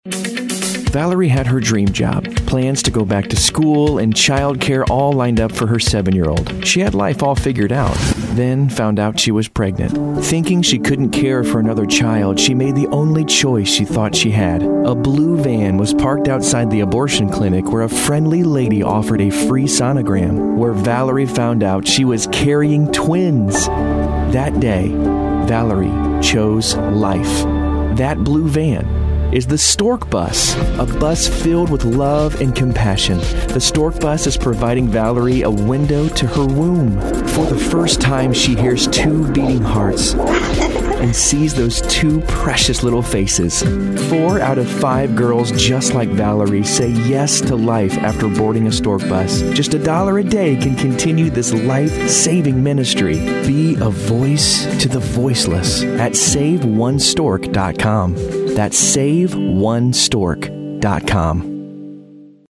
Male
I have a conversational, natural and Everyman type of voice.
Radio Commercials
Words that describe my voice are Conversational, Natural, Everyman.